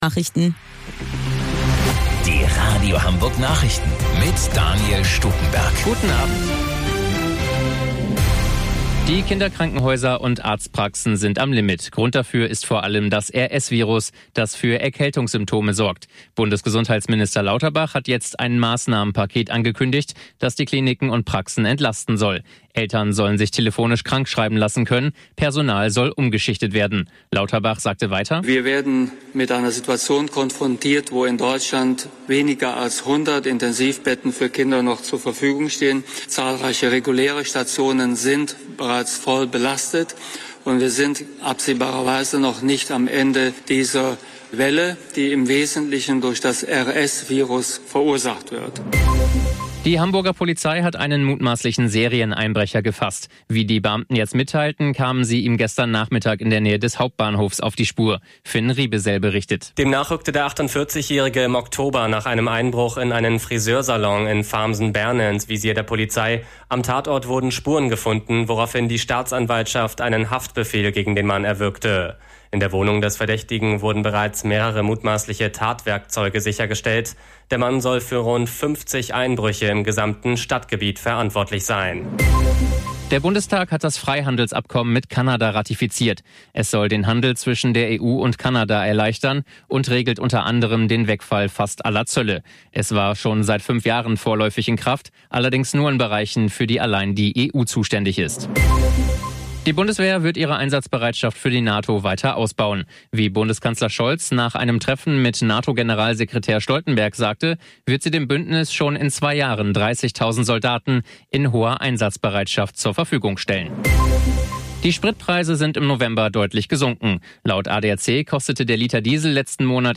Radio Hamburg Nachrichten vom 29.09.2022 um 03 Uhr - 29.09.2022